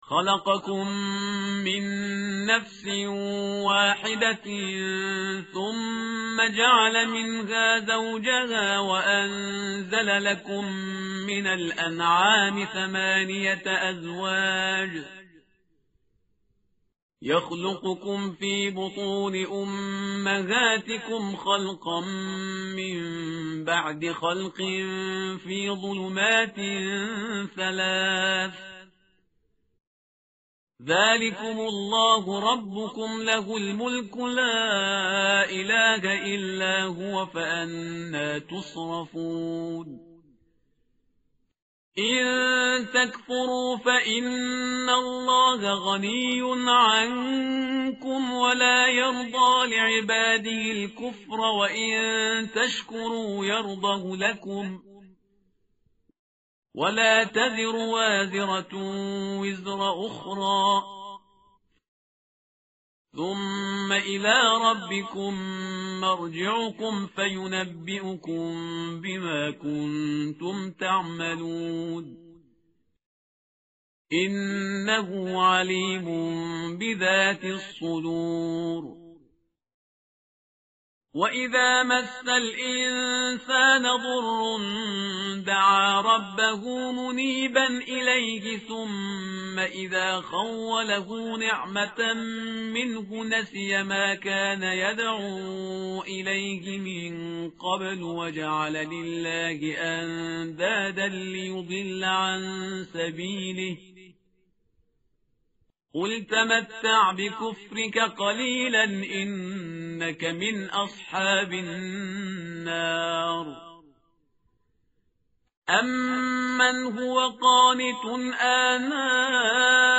متن قرآن همراه باتلاوت قرآن و ترجمه
tartil_parhizgar_page_459.mp3